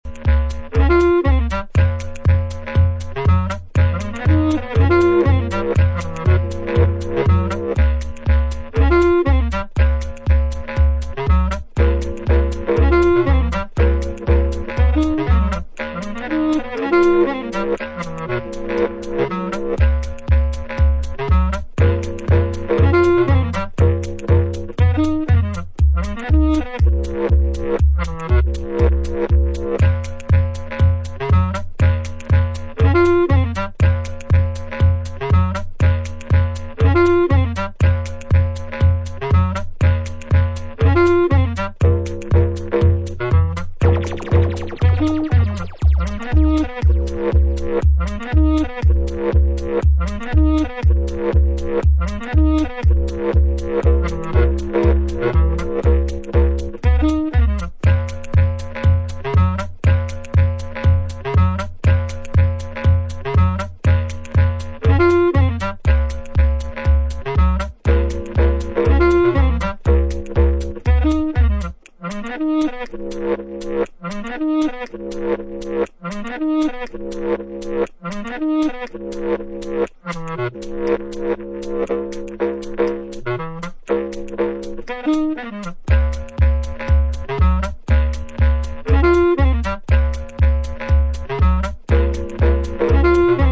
supplier of essential dance music
House